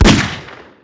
357 Gunshot.wav